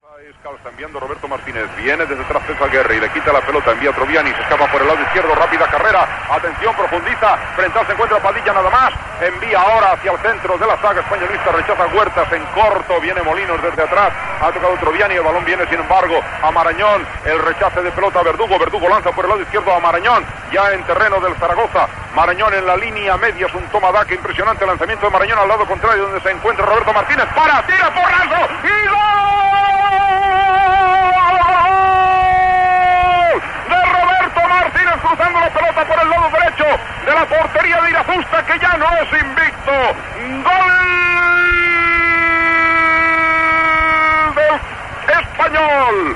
Transmissió, des de Saragossa, del partit de la lliga masculina de la primera divisió de futbol entre el Zaragoza i el Real Club Deportivo Espanyol.
Narració del gol de Roberto Martínez per a l'Espanyol.
Esportiu